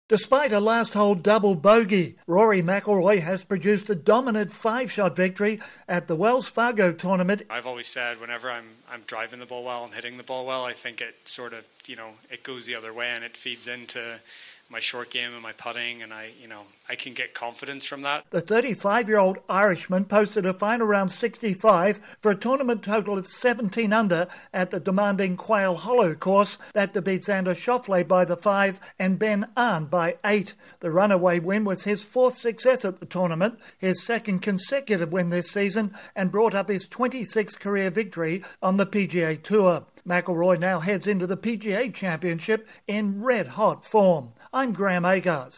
Rory Mcilroy adds another tournament to his resume. Correspondent